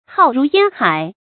浩如煙海 注音： ㄏㄠˋ ㄖㄨˊ ㄧㄢ ㄏㄞˇ 讀音讀法： 意思解釋： 浩：廣大；眾多；煙海：茫茫大海。